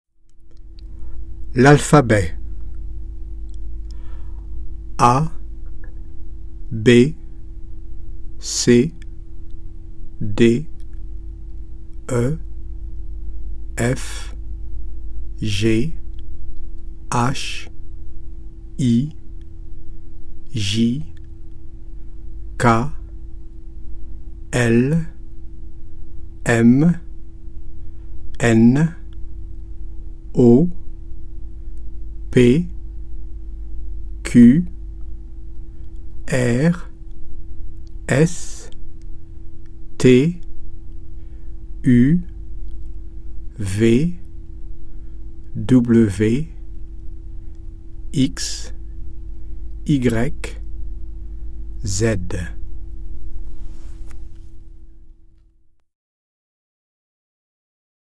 Zkrátka, na samém začátku se neobejdeme bez abecedy. Poslechněte si, jak se ve francouzštině hláskuje.